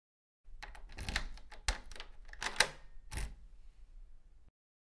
دانلود صدای قفل در از ساعد نیوز با لینک مستقیم و کیفیت بالا
جلوه های صوتی
برچسب: دانلود آهنگ های افکت صوتی اشیاء دانلود آلبوم صدای قفل کردن در از افکت صوتی اشیاء